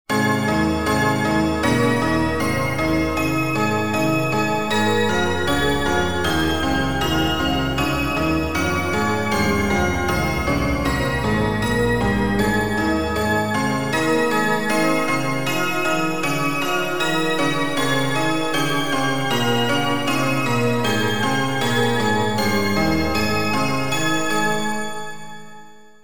発車メロディー一覧
2番線 普通･急行ホーム 南緋嵜･漆黒のめたん･臘花中央方面(一部 桜乃方面)